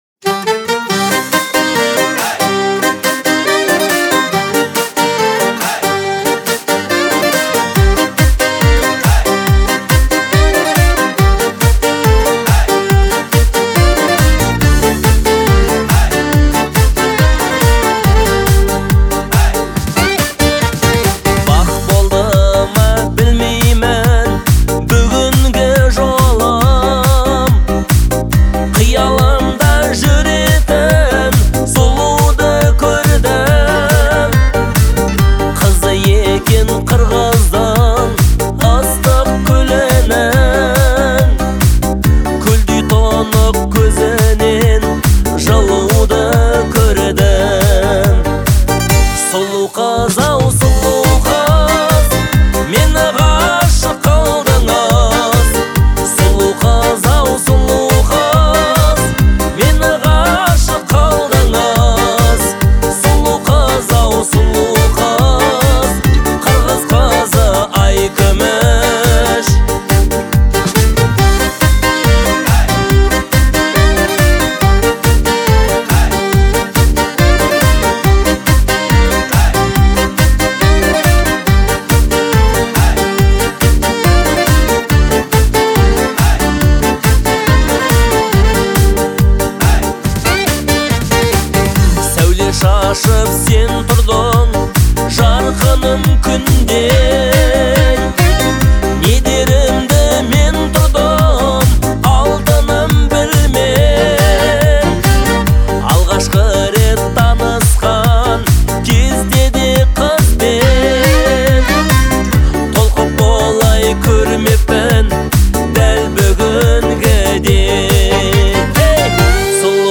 Настроение трека — меланхоличное, но полное надежды.
обладая выразительным голосом, передает глубокие эмоции